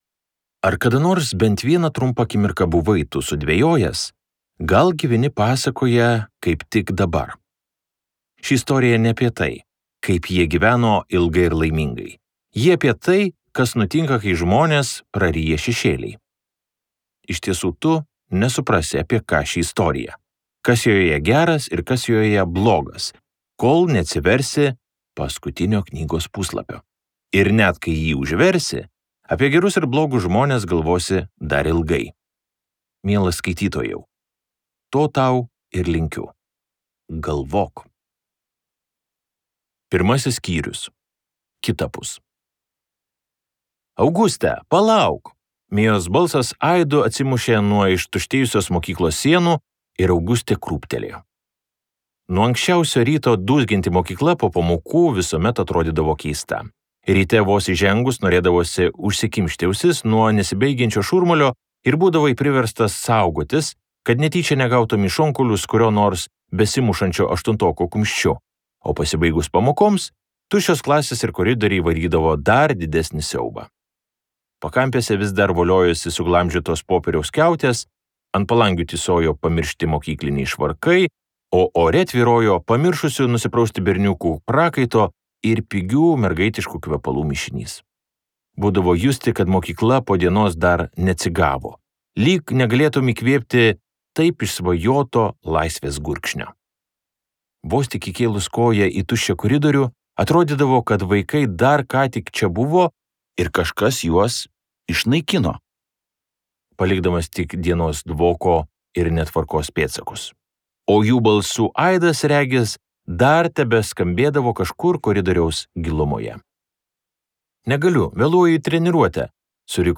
Dvylika kruvinų plunksnų | Audioknygos | baltos lankos